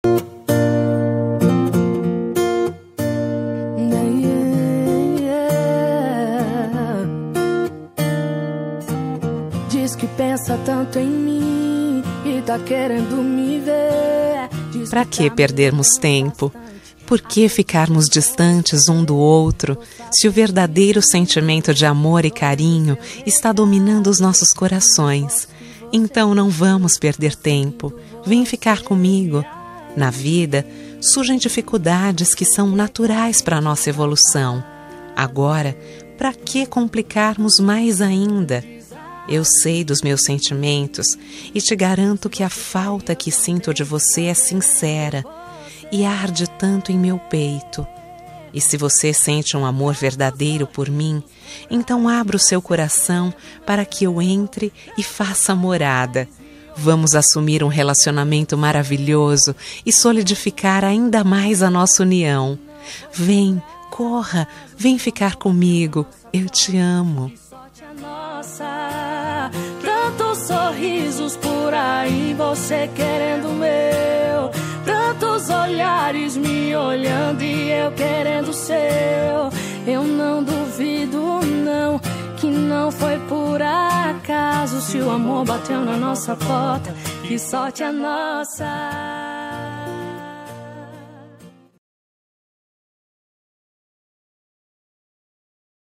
Telemensagem de Pedido – Voz Feminina – Cód: 1892 – Quer Namorar